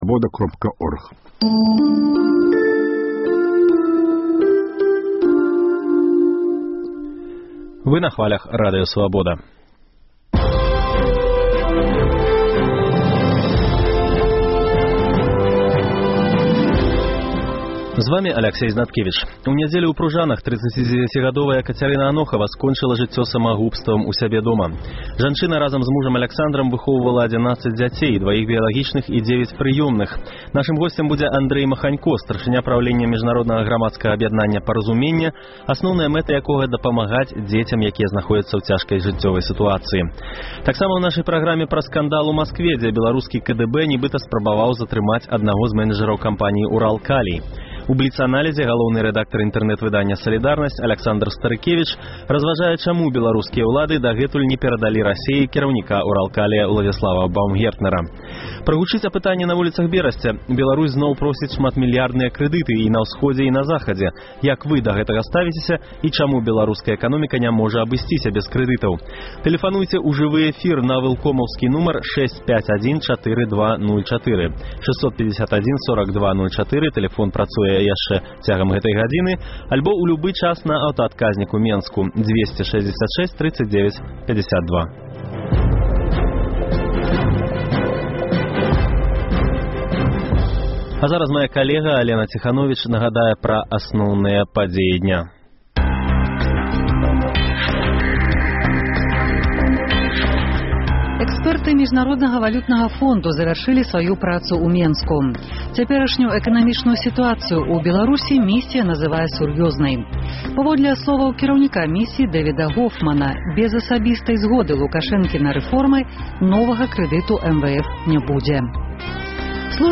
Прагучыць апытаньне на вуліцах Берасьця: Беларусь зноў просіць шматмільярдныя крэдыты